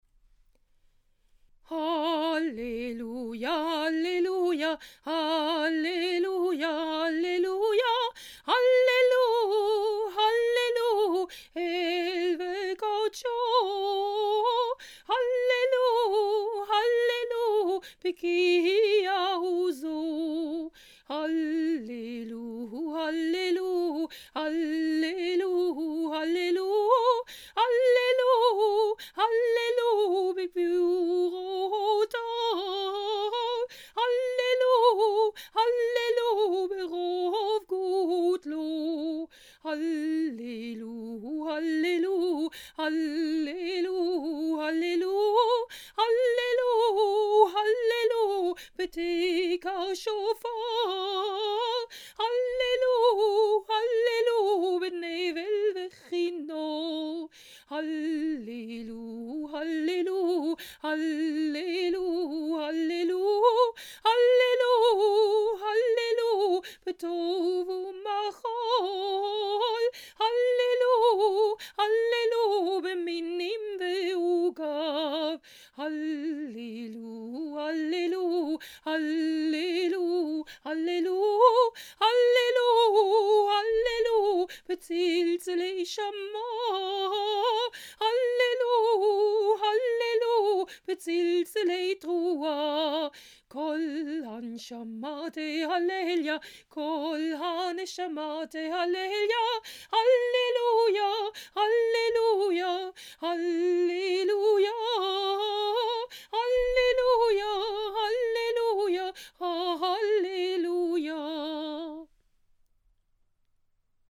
P 107                                     A tune of your choice for Psalm 150         (any tune in major)
(often chosen as there are many congregational melodies)
TUNE 1: CONGREGATIONAL TUNE Halleluja